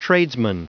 Prononciation du mot tradesman en anglais (fichier audio)
Prononciation du mot : tradesman